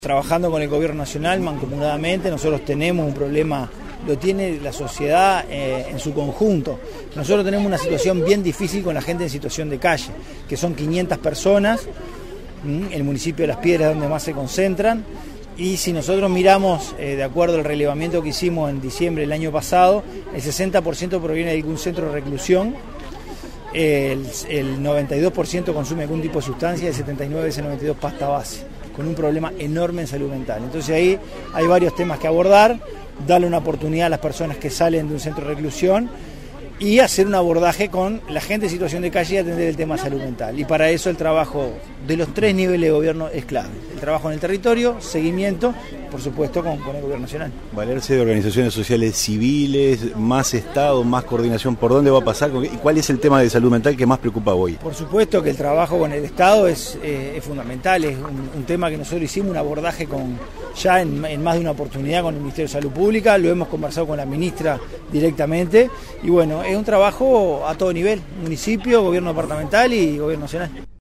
La gente en situación de calle y el problema del consumo es lo más preocupante hoy, dijo el Intendente de Canelones, Francisco Legnani.